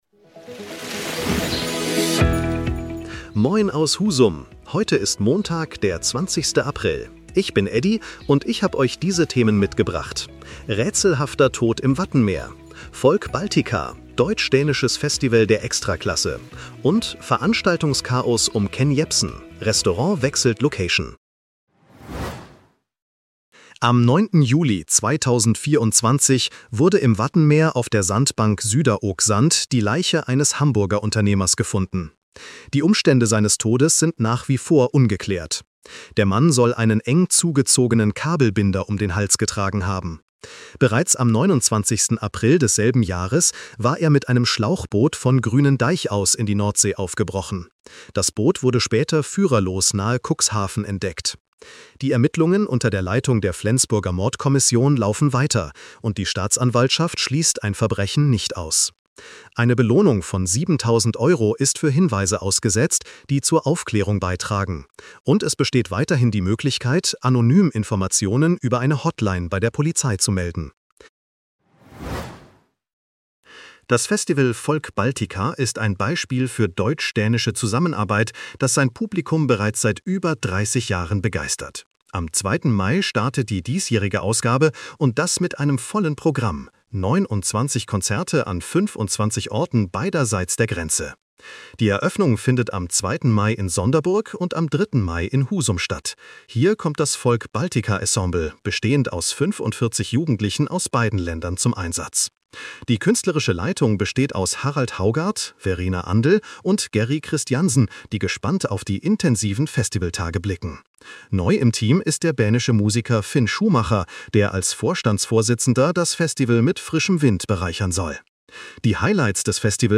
Was bewegt Husum heute? In unserem regionalen Nachrichten-Podcast